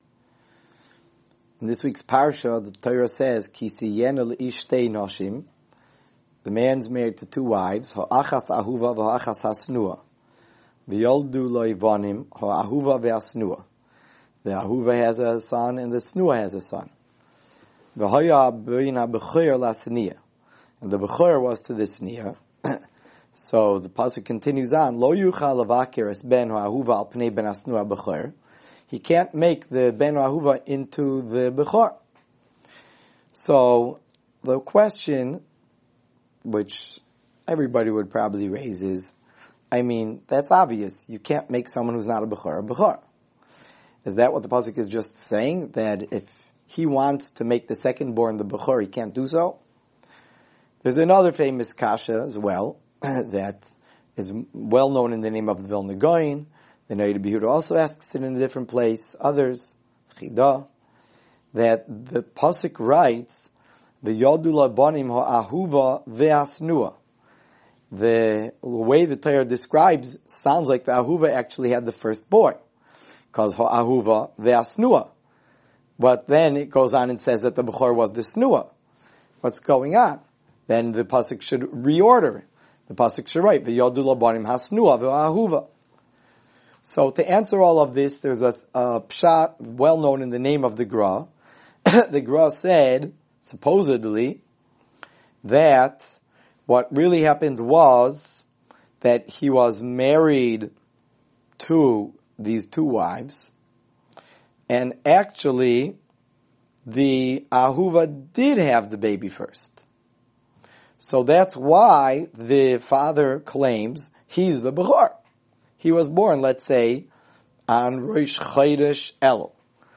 Weekly Alumni Shiur - Ner Israel Rabbinical College